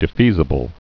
(dĭ-fēzə-bəl)